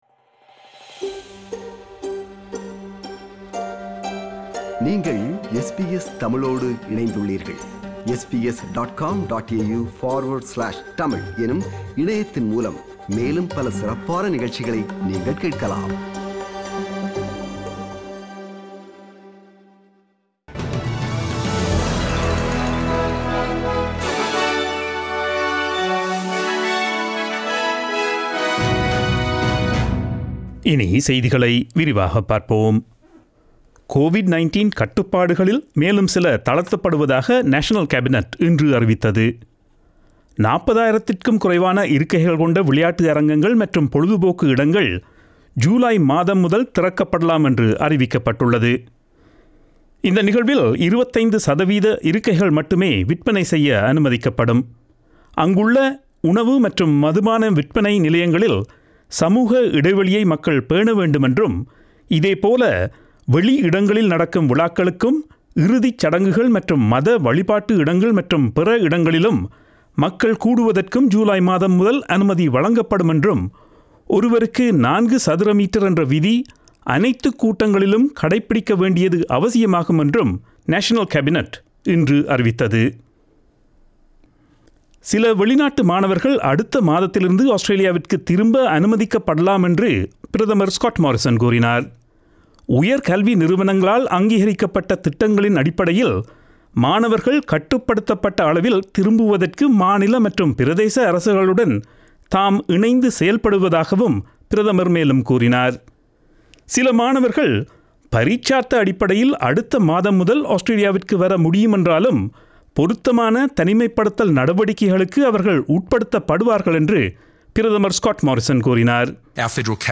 Australian news bulletin aired on Friday 12 June 2020 at 8pm.